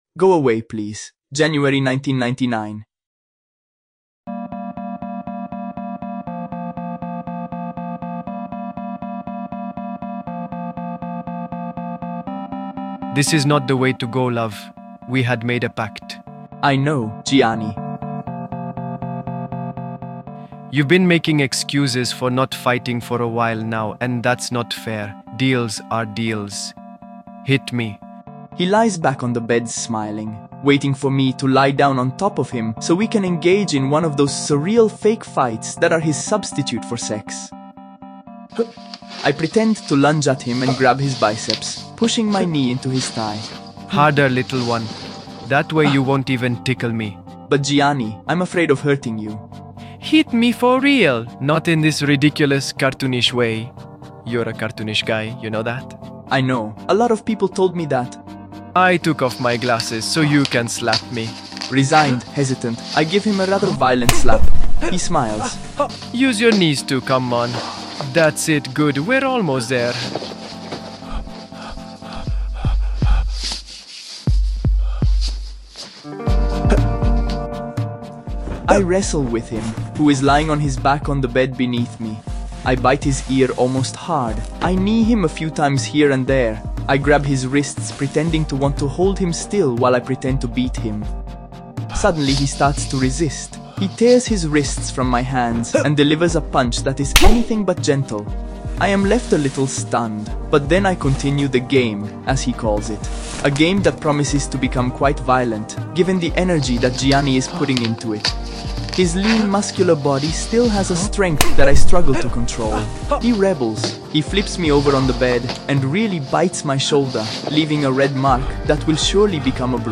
We have remade this episode with new voices.